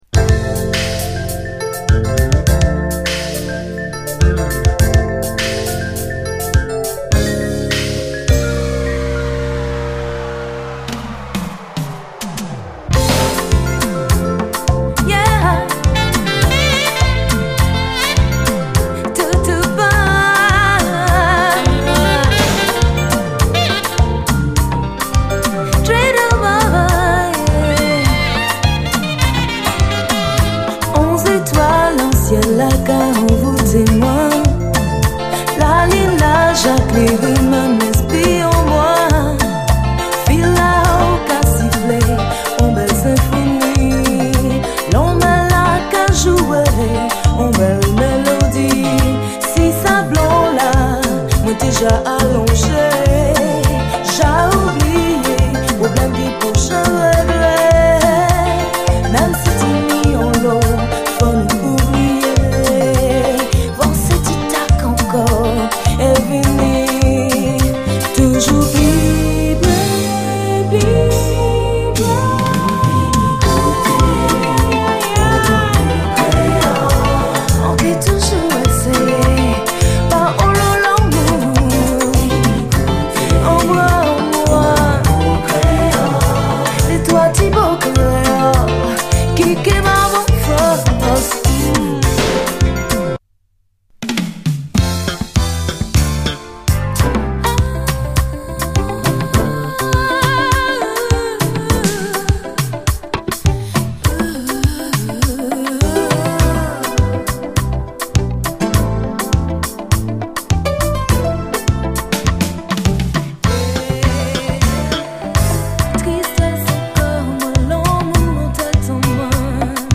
ピュンピュン鳴りまくる
弾き直しのシンセ・ブギー・トラックに燃えずにいられない！